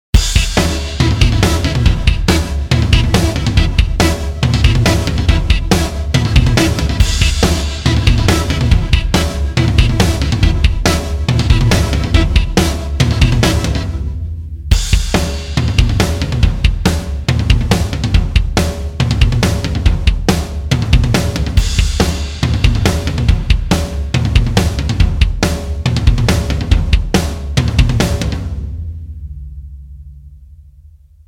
UltraTap | Drums | Preset: Cyber BB
UltraTap-Cyber-BB-drums.mp3